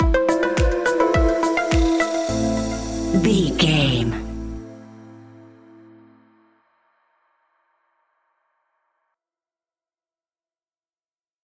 Evening Tropical House Stinger.
Aeolian/Minor
groovy
calm
smooth
dreamy
uplifting
piano
drum machine
synthesiser
house
synth bass